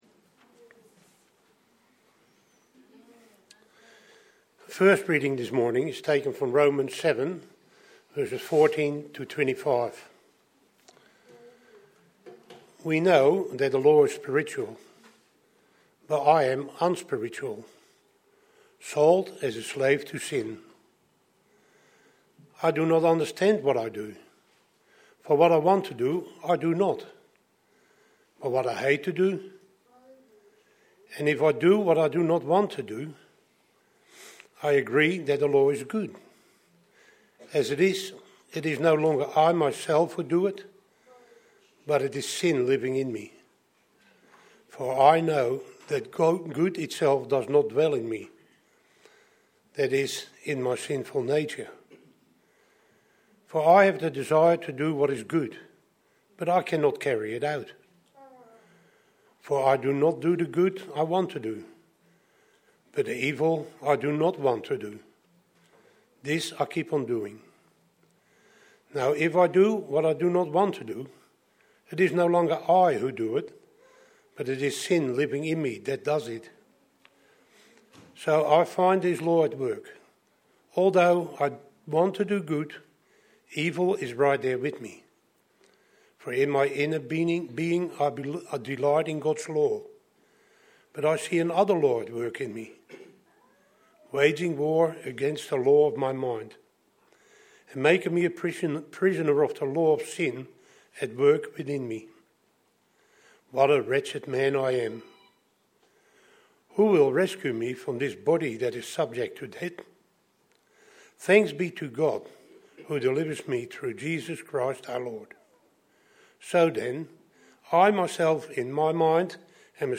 Sermons | Living Water Anglican Church